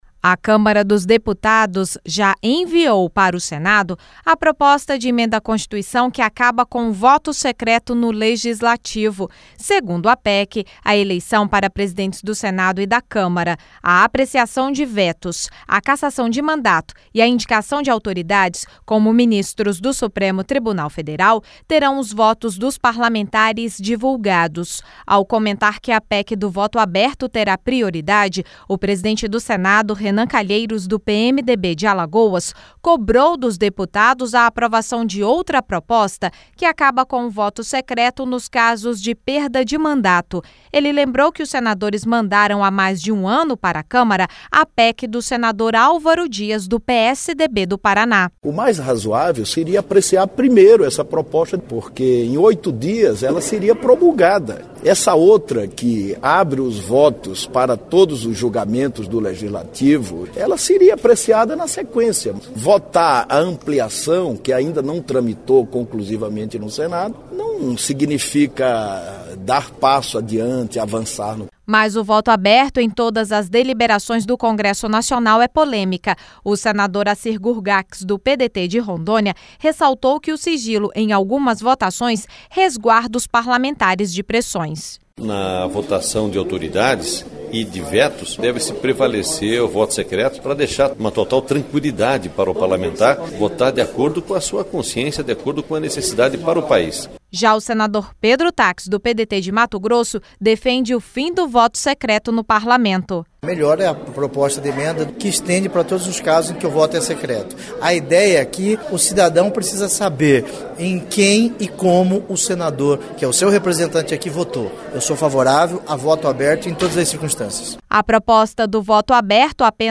O senador Acir Gurgacz do PDT de Rondônia ressaltou que o sigilo em algumas votações resguarda os parlamentares de pressões.